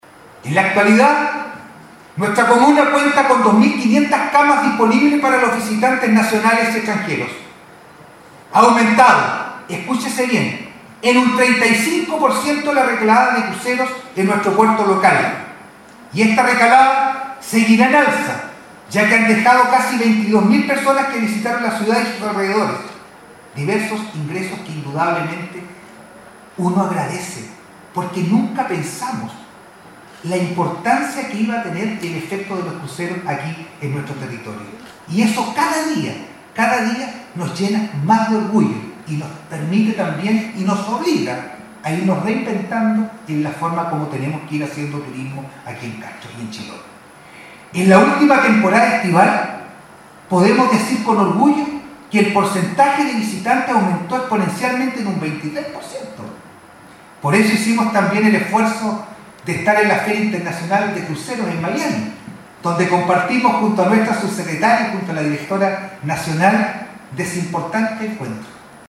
Poniendo en perspectiva de futuro el sector turístico de Chiloé y formulando un llamado para potenciar ese mismo sector, el alcalde de Castro Juan Eduardo Vera inauguró el congreso número 41 de la Asociación Chilena de Empresas de Turismo, ACHET, que sesionará en la provincia de Chiloé hasta el 22 de octubre próximo.
CUÑA-ALCALDE-VERA-CONGRESO-ACHET-2.mp3